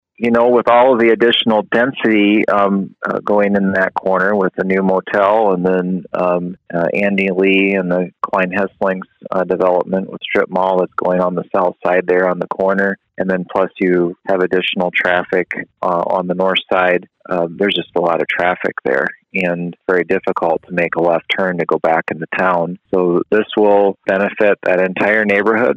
Kooiker says with the growth of the eastern part of the City, the traffic light is much needed.